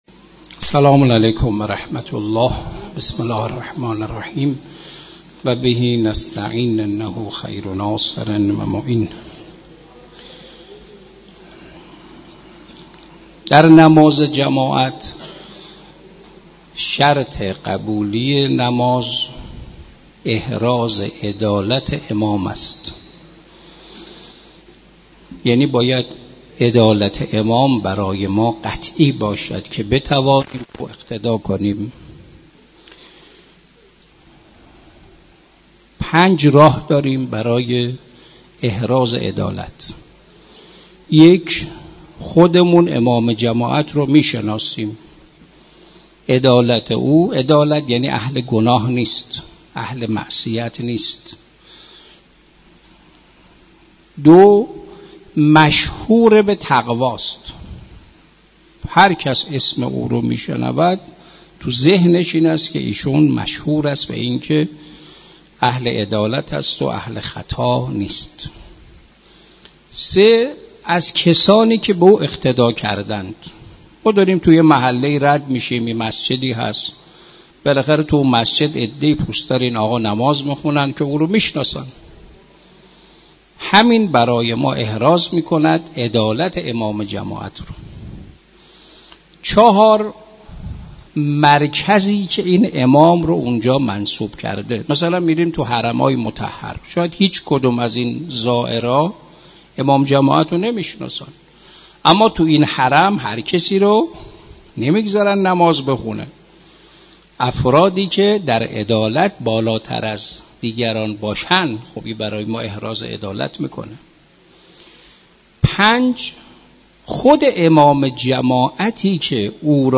در مسجد دانشگاه